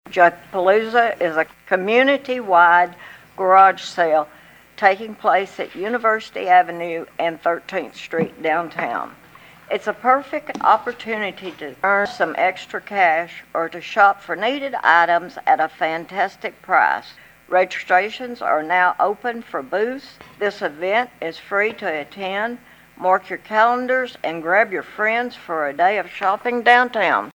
Councilmember Pat Graham passed along more info at the last council session . . . .